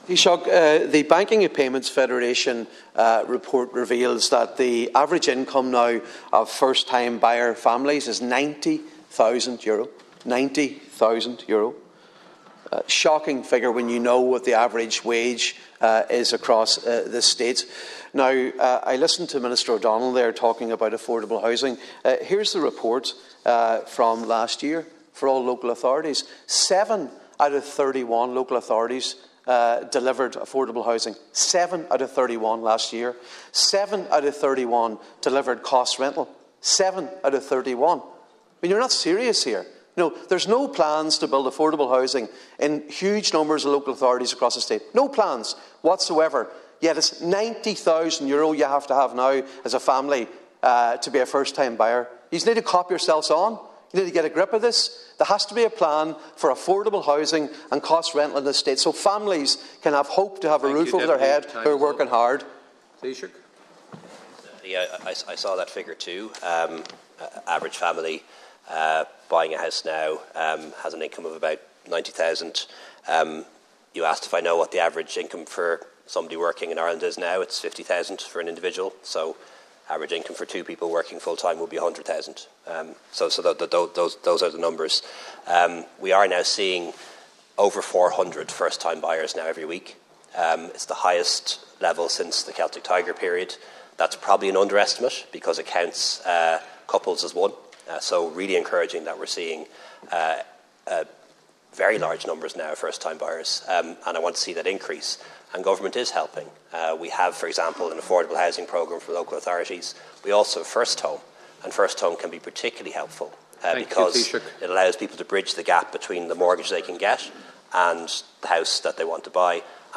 In a heated address, Deputy Padraig MacLochlainn highlighted findings in a report from the Banking and Payments Federation noting that the average income of first-buyer families is €90,000.